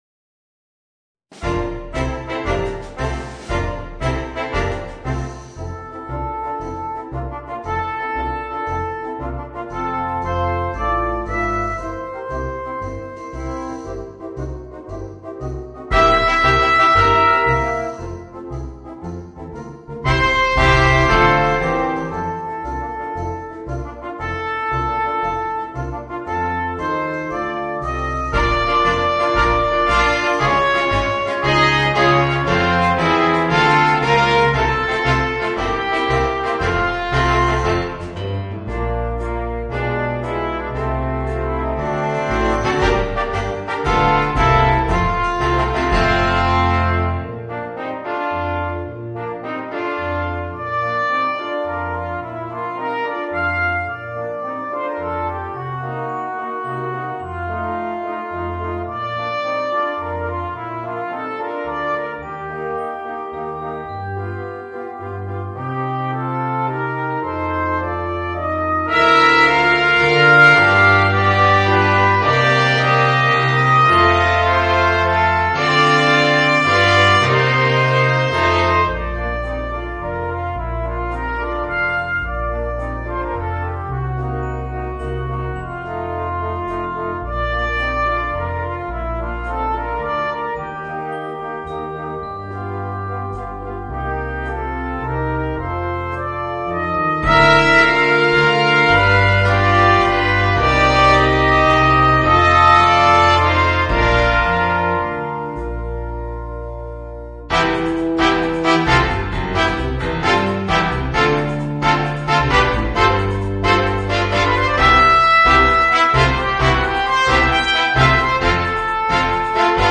Voicing: 2 Trumpets, 2 Trombones